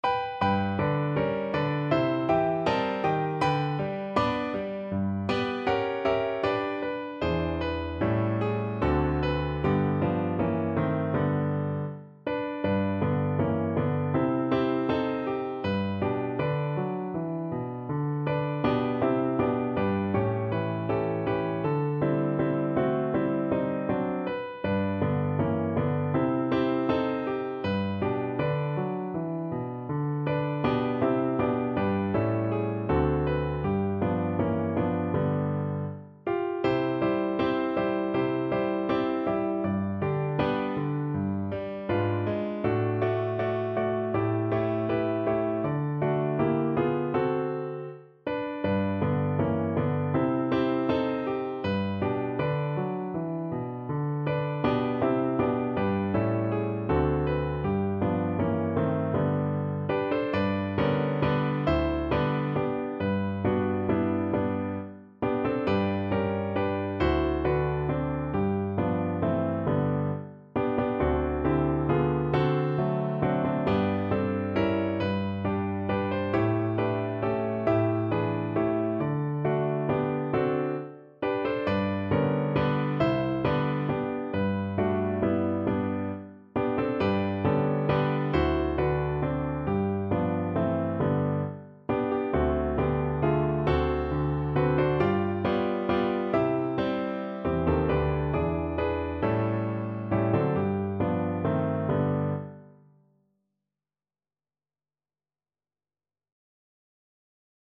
No parts available for this pieces as it is for solo piano.
4/4 (View more 4/4 Music)
Andante
Piano  (View more Intermediate Piano Music)
Pop (View more Pop Piano Music)